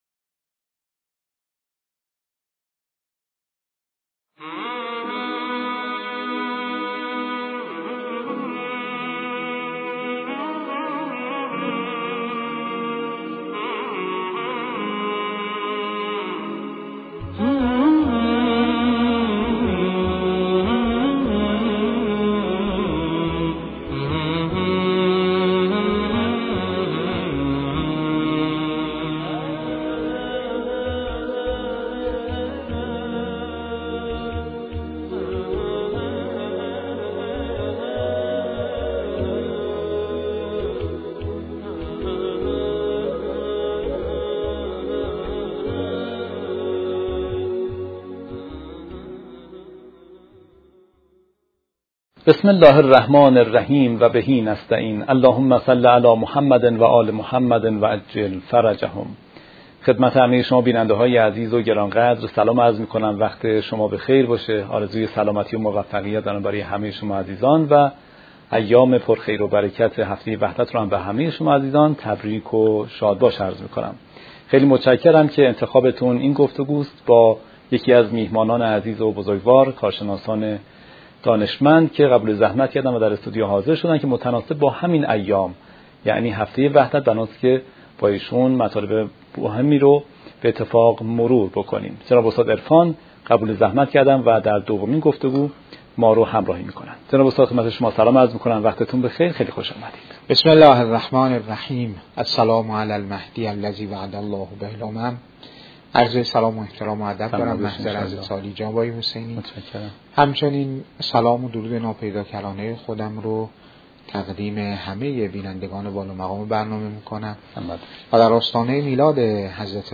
میزبان با سلام و تحیت به بینندگان، فرارسیدن هفته وحدت را تبریک گفته و از مهمان برنامه،